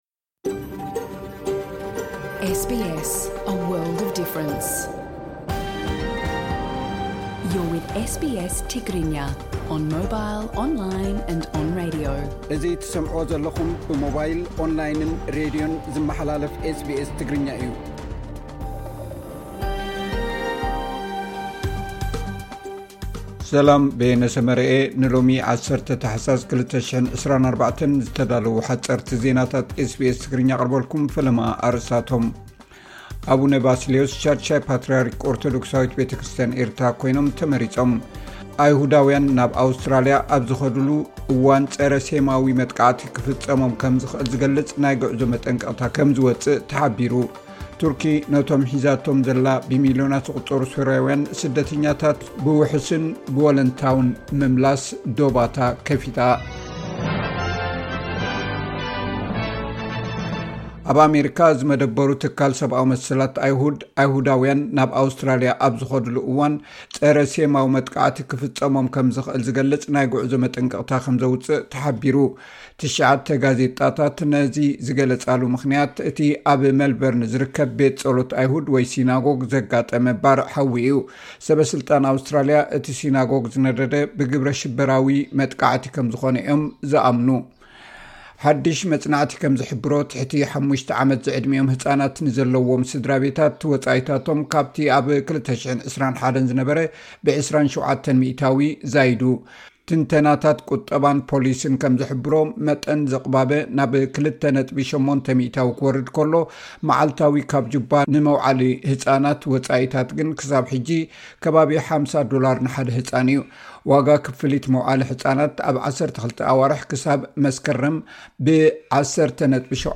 ኦርቶዶክሳዊት ቤተክርስትያን ኤርትራ 6ይ ፓትርያርክ መሪጻ፡ ሓጸርቲ ዜናታት ኤስ ቢ ኤስ ትግርኛ (10 ታሕሳስ 2024)